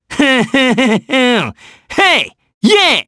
Gladi-Vox_Hum_jp_b.wav